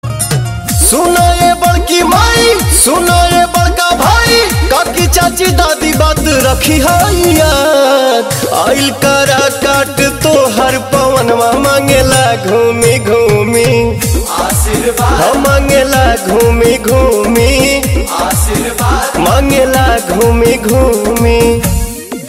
Bhojpuri Album Ringtones